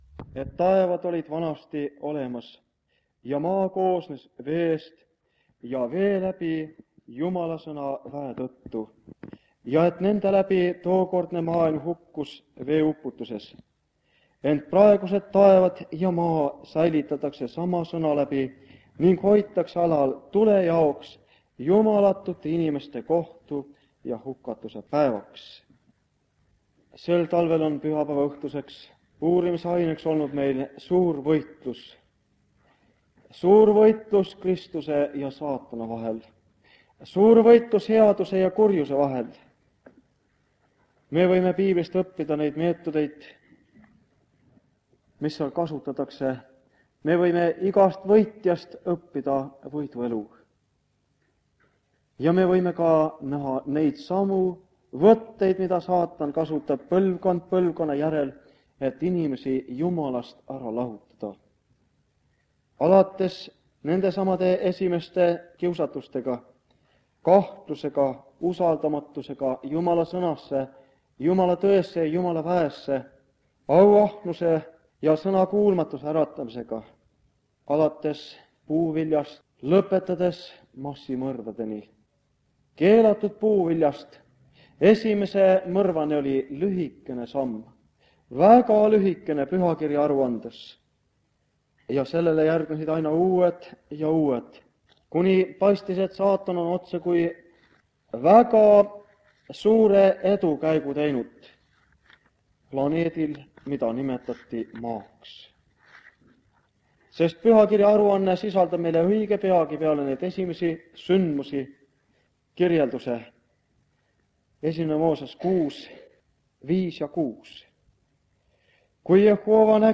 Jutlused
Koosolekuid peeti Saaremaal Kingissepa linna (Kuressaare) adventkoguduses 1976-77 aastal.